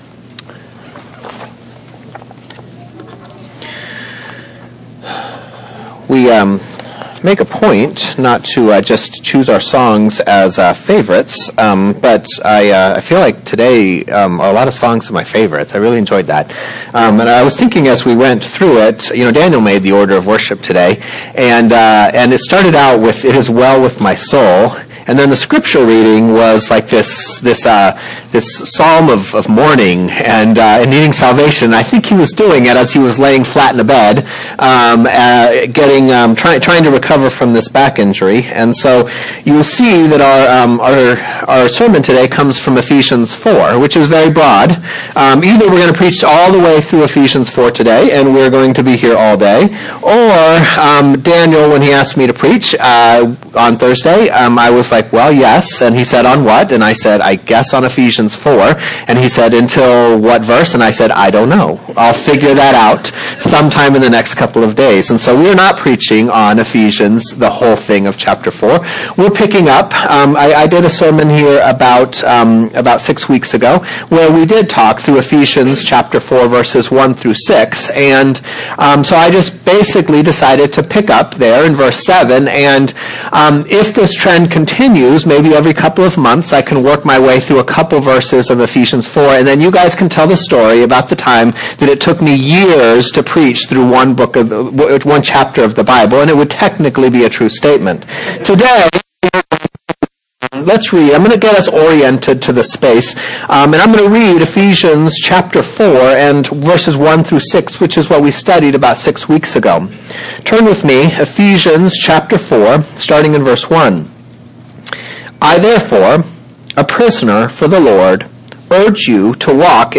2017 ( Sunday AM ) Bible Text